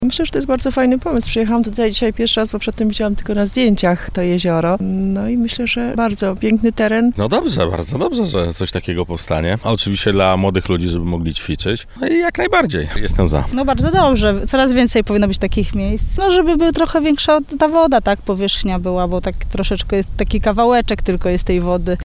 15-05-mieszkancy.mp3